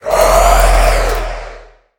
1.21.5 / assets / minecraft / sounds / mob / wither / idle2.ogg